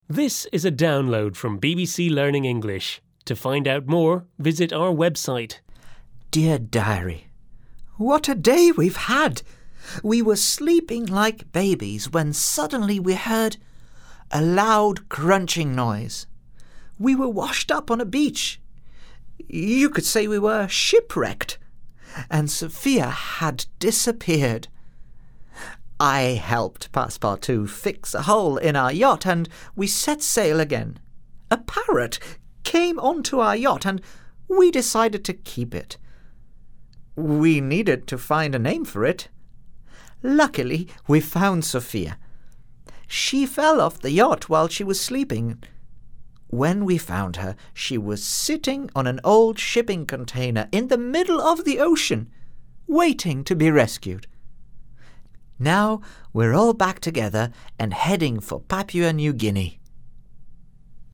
unit-7-4-1-u7_eltdrama_therace_audio_diary_download.mp3